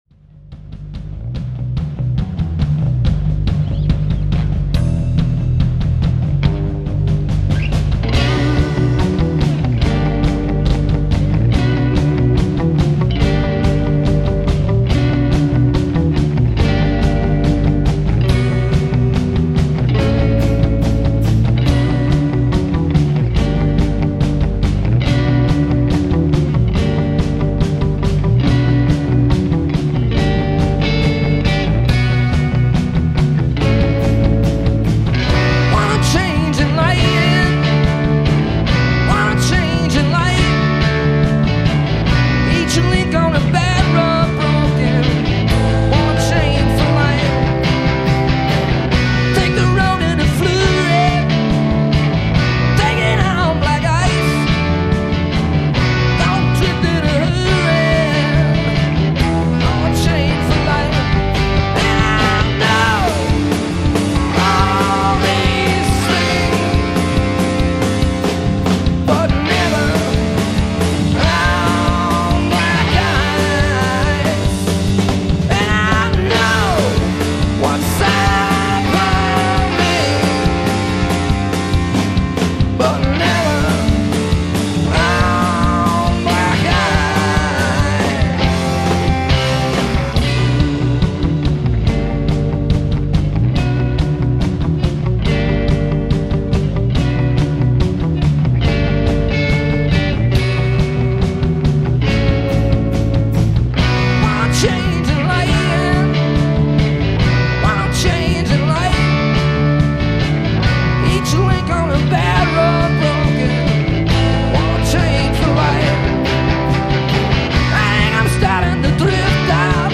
American Garage-Rock/Punk/Indie/Twang practitioners